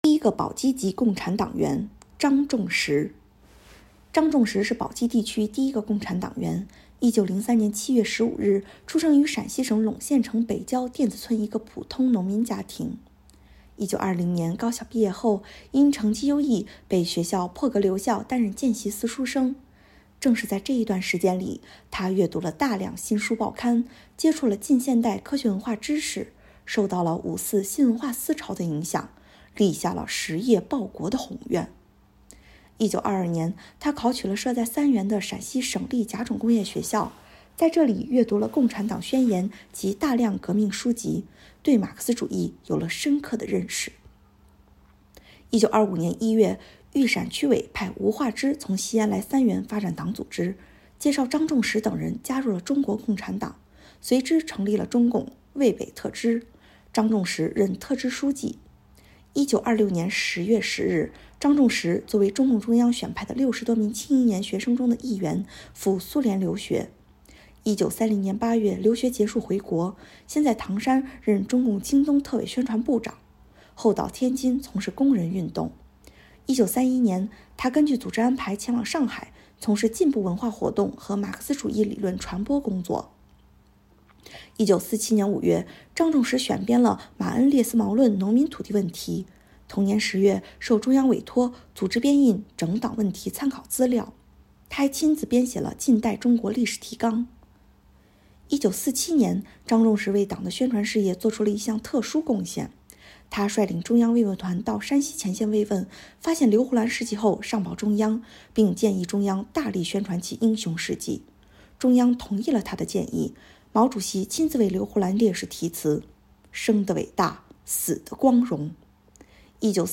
【红色档案诵读展播】第一个宝鸡籍共产党员——张仲实